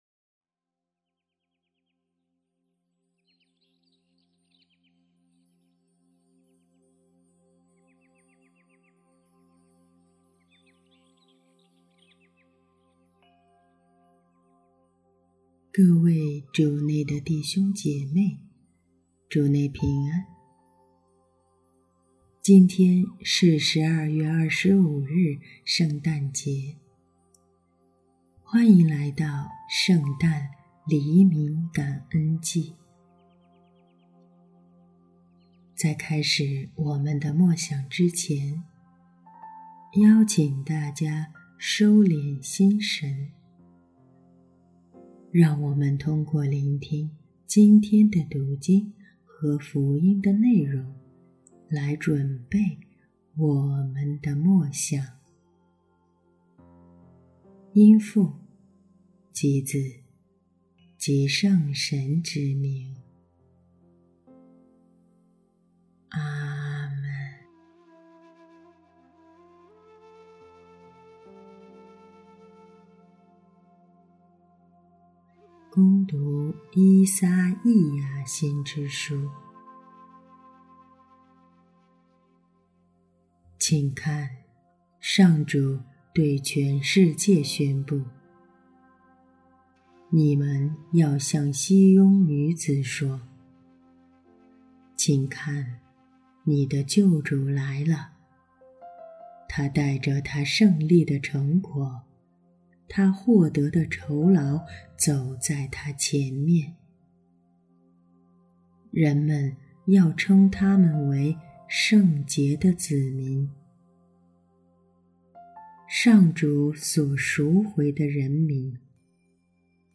首页 / 证道